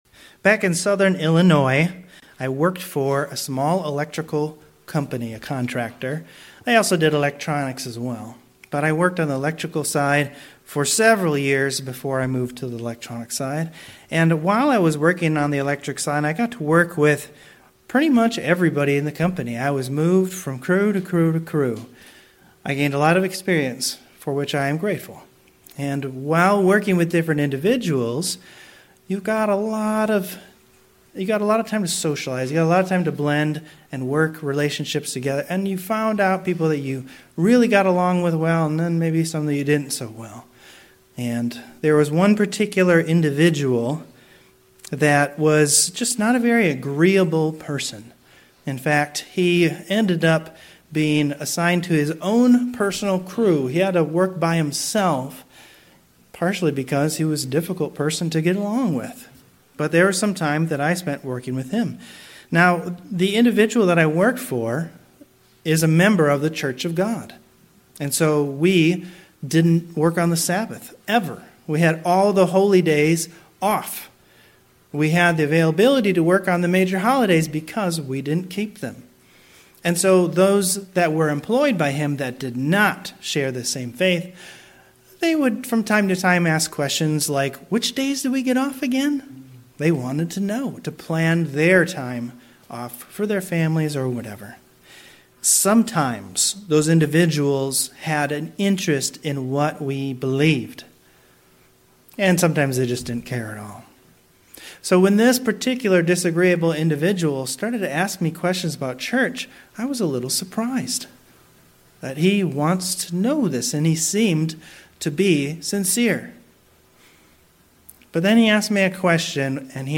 Given in Cleveland, OH